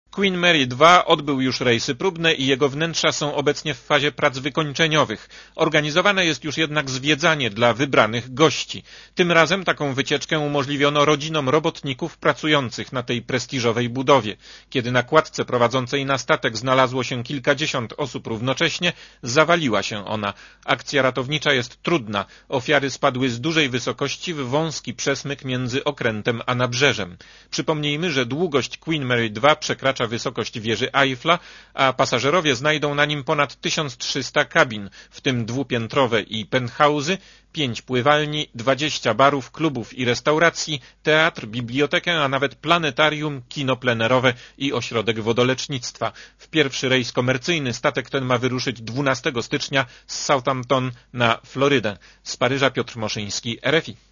Posłuchaj relacji francuskiego korespondenta Radia Zet